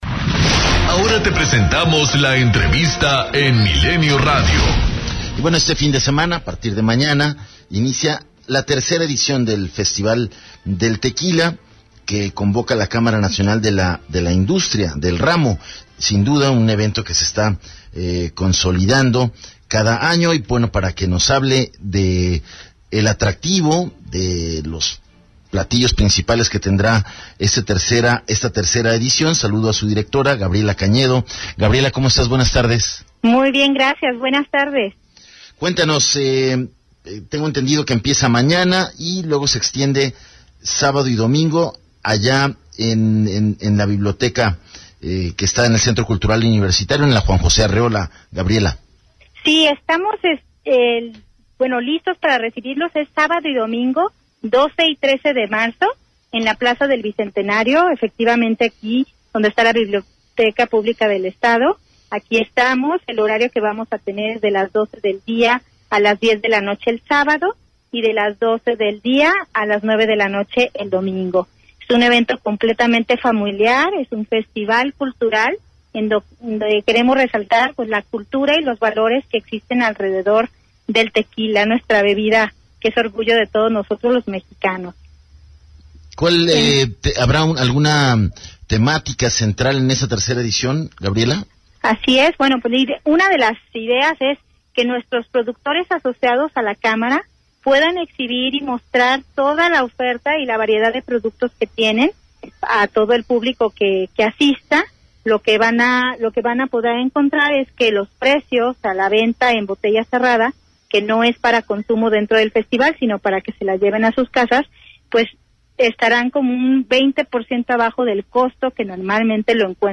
ENTREVISTA 100316